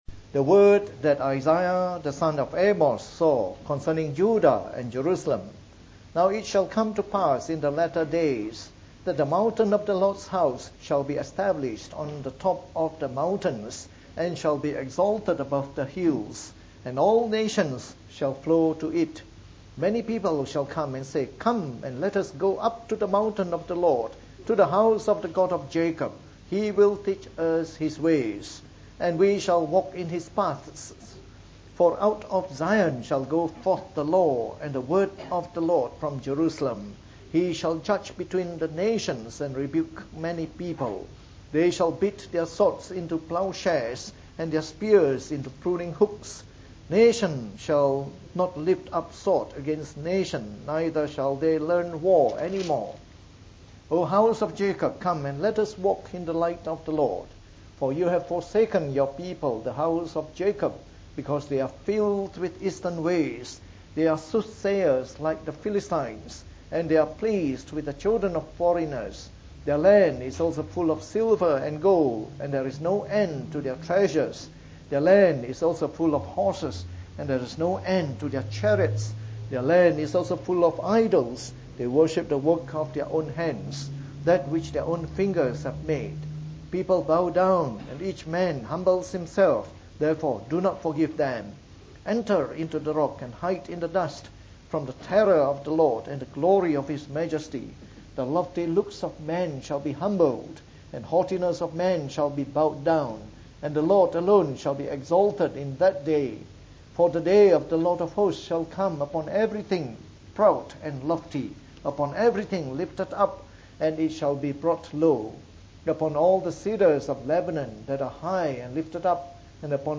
From our new series on the book of Isaiah delivered in the Morning Service.